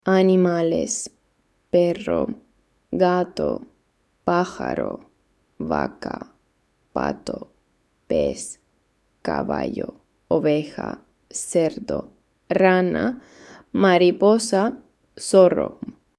Lesson 6